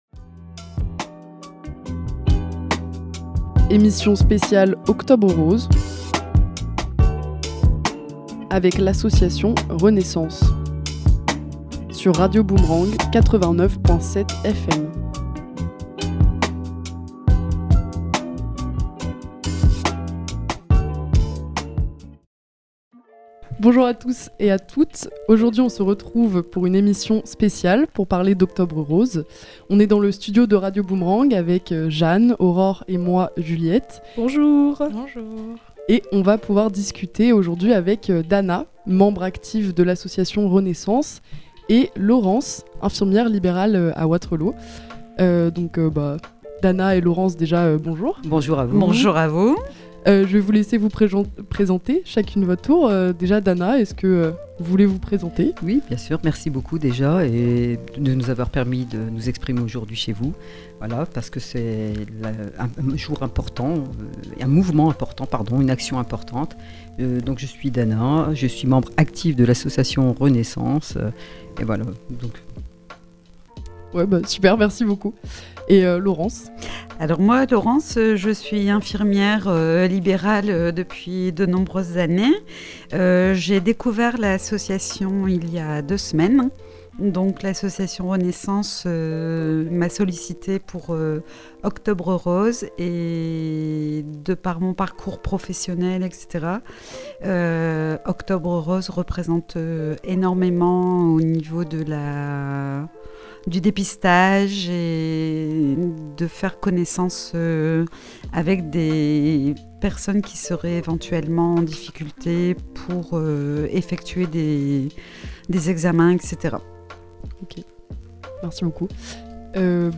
Émission spéciale sur l'enseignement du picard, 5 ans après la loi Molac